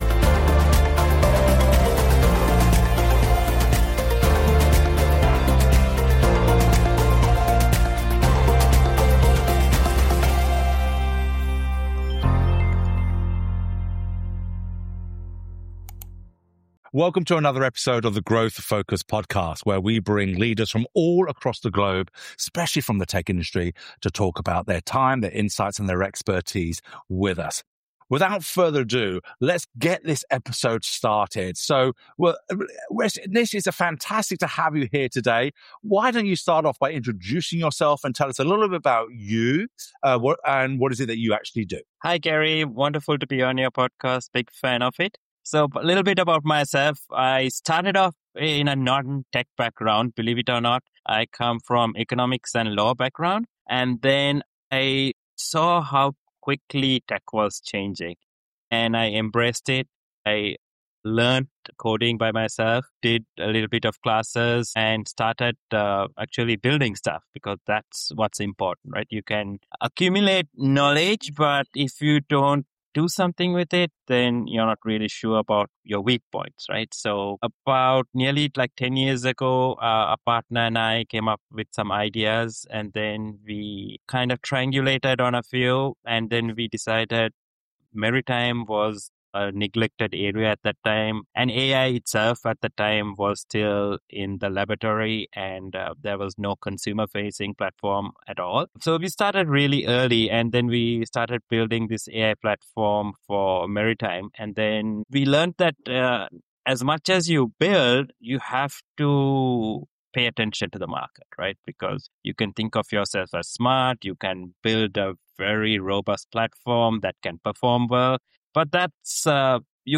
This conversation gets into what actually creates growth in tech right now, why deep client relationships are still a competitive advantage, and how AI agents could reshape buying behavior faster than most companies are prepared for.